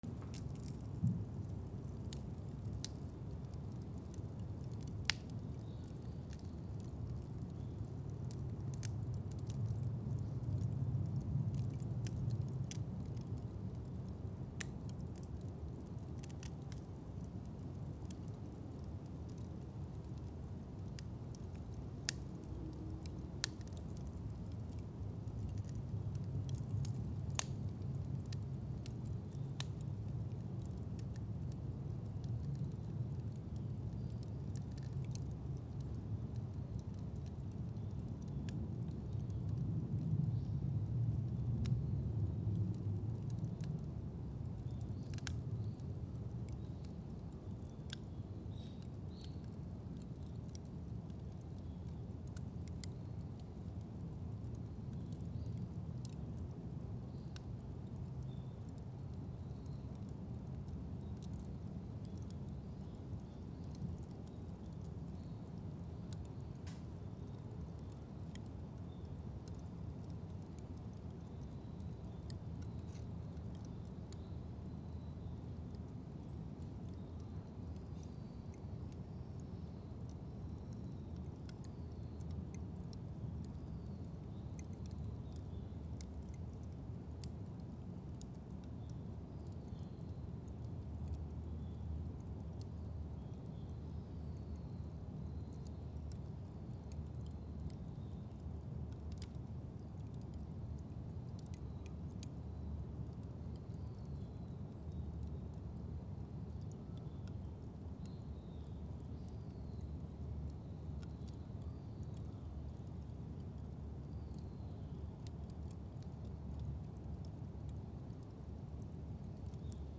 35炭火
/ C｜環境音(人工) / C-42 ｜火を燃やす / 2_D50